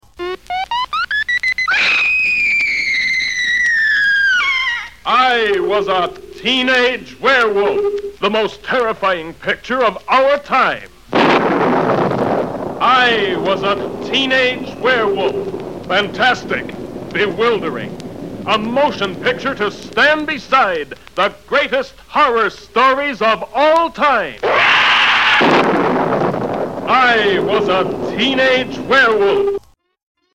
Howl With Some Werewolf Movie Radio Spots